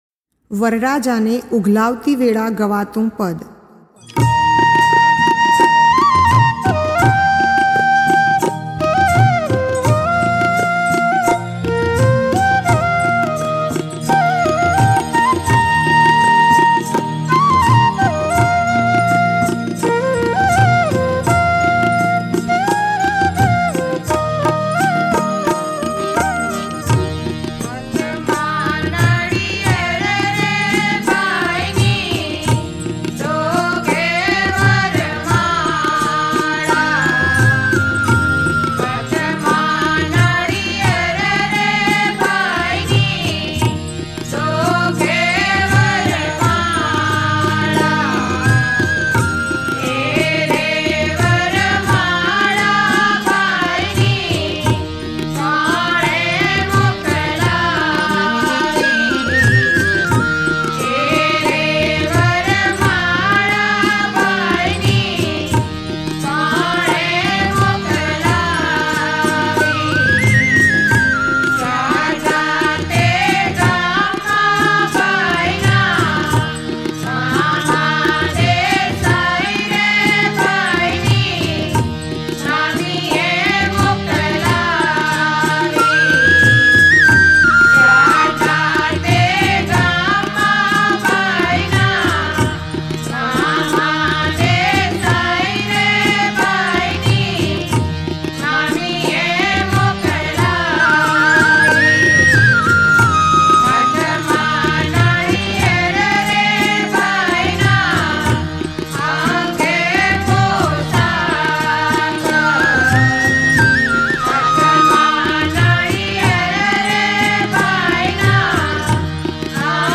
વરરાજાને ઉઘલાવતી વેળા ગવાતું પદ ...હાથમાં નાળિયેર રે ભાઈની ...